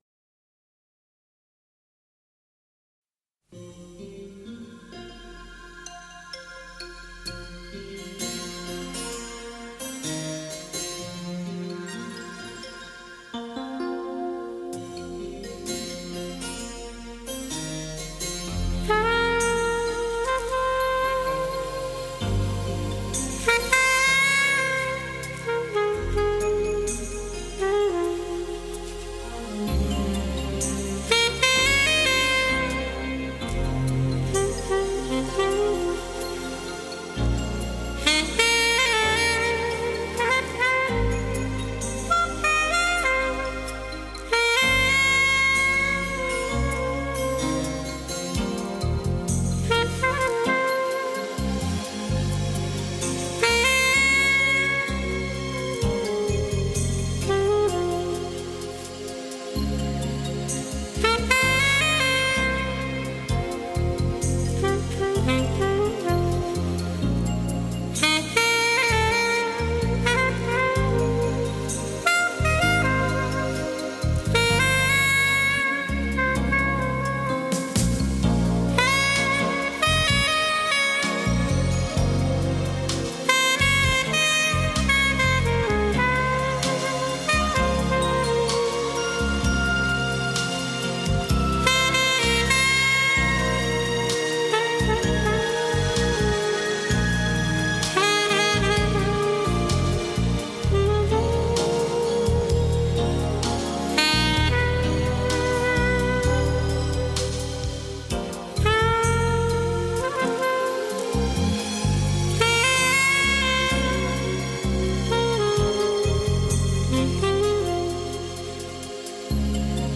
radiomarelamaddalena / STRUMENTALE / SAX /